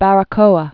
(bărə-kōə, bärä-)